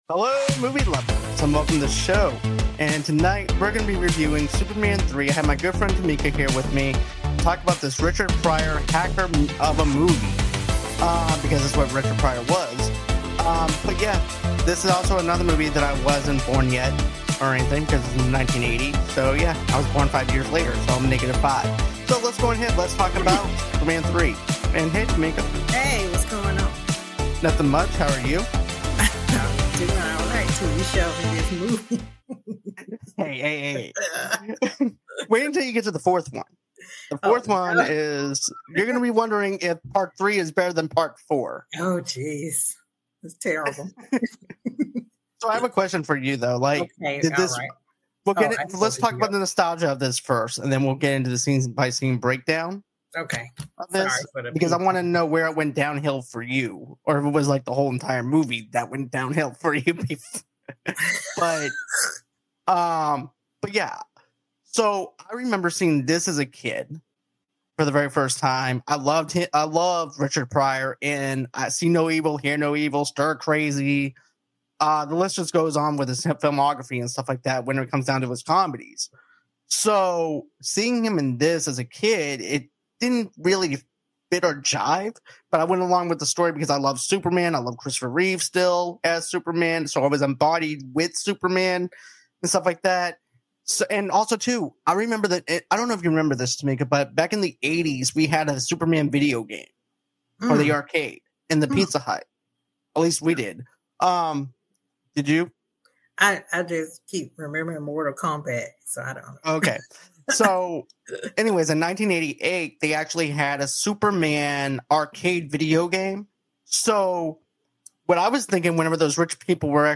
Edward Scissorhands (1990) Review